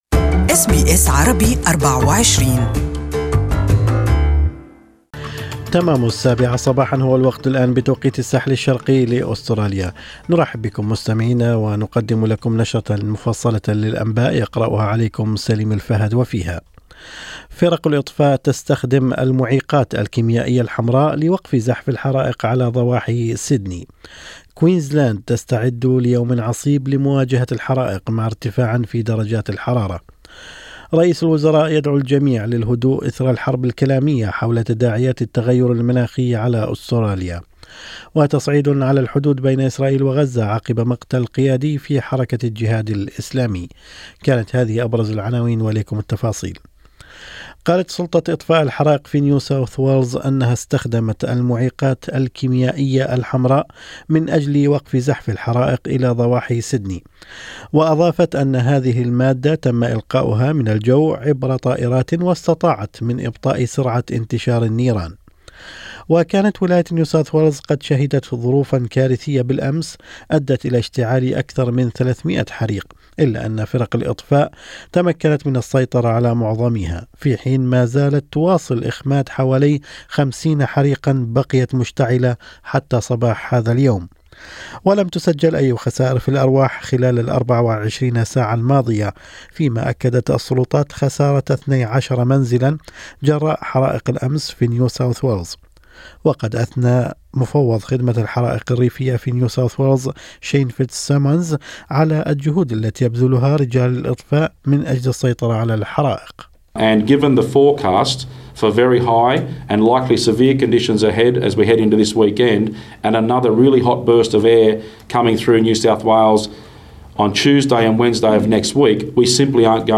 Morning News: NSW remains in a state of emergency amid warnings of 'volatile' conditions